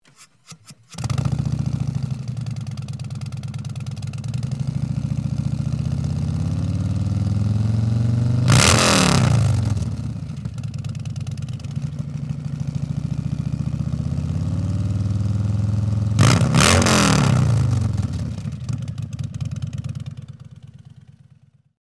・深く歯切れの良いサウンドを実現します。
2025_Softail_Slip-On.mp3